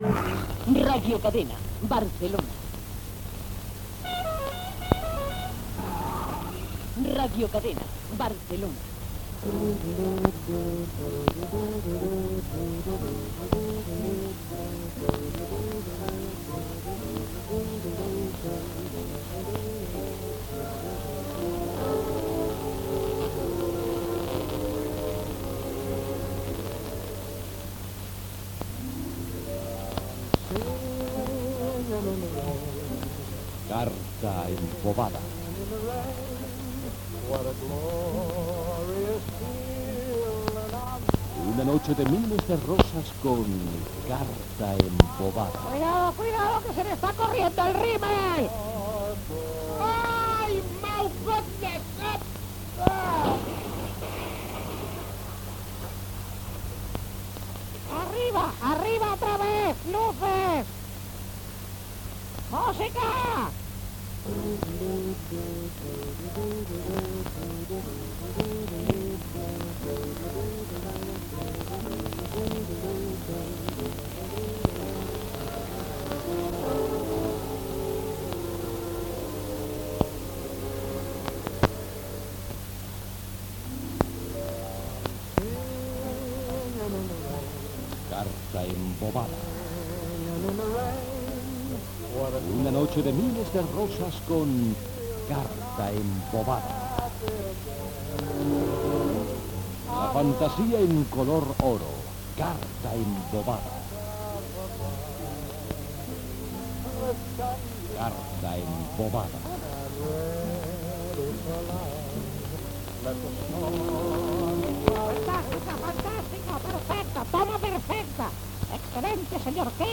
Gènere radiofònic Entreteniment
So defectuós.